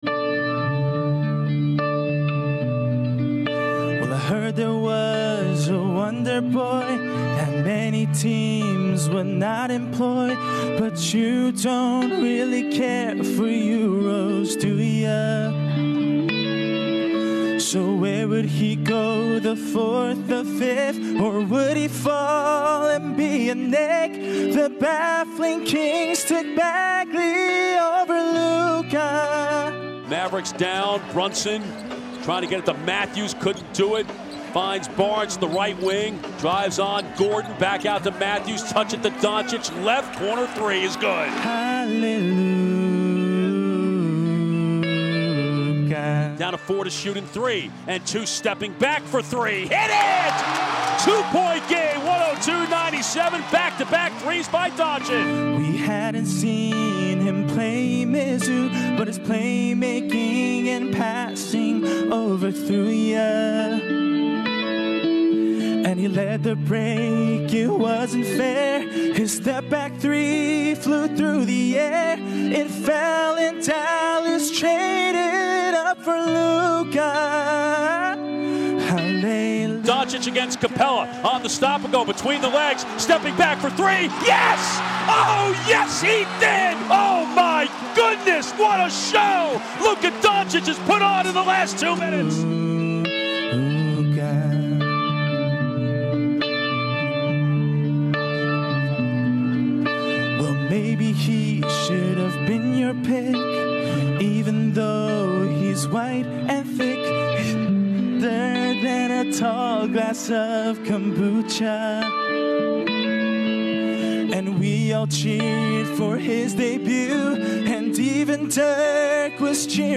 Enjoy the live rendition